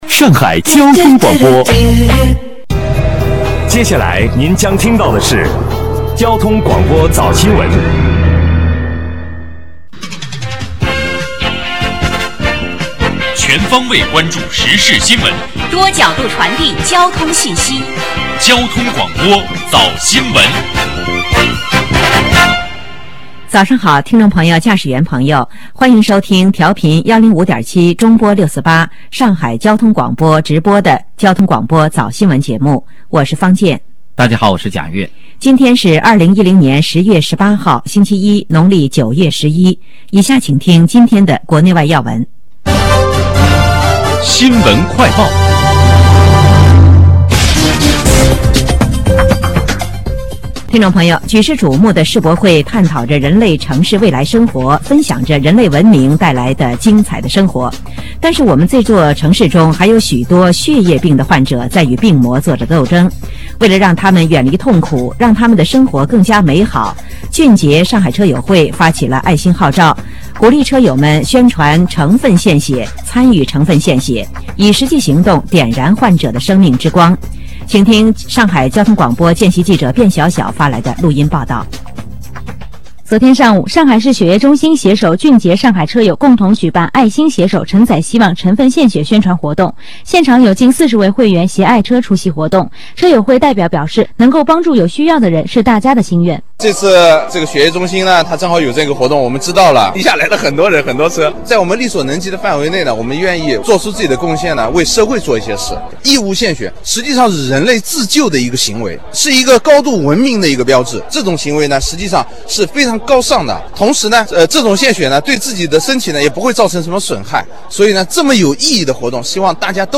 105.7交通台新闻链接：爱心携手 承载希望.mp3